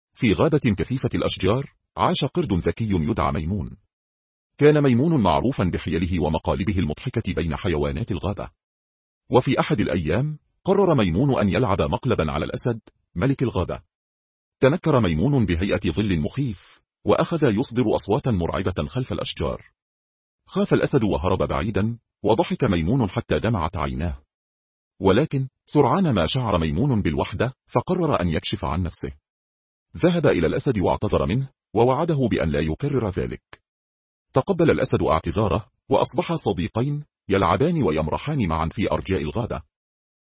أمثلة لنتائج مولد الأصوات المجاني المتقدم VocalAI
٢. تعليق صوتي بلهجة ذكر عراقي، مع نغمة رسمية هادئة (الإعداد -13 & السرعة 25)